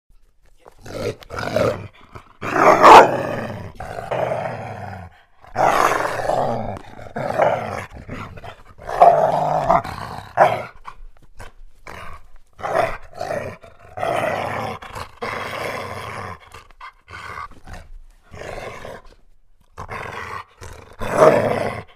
Звук - Рычание собаки
Отличного качества, без посторонних шумов.